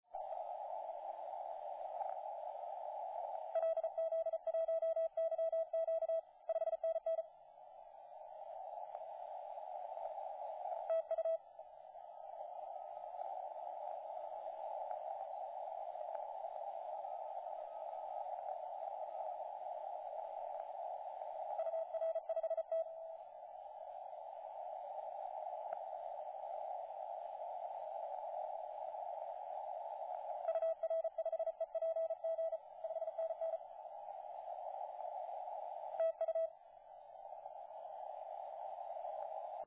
In the afternoon 9M0W was booming on 80. About 20 dBs stronger than yesterday: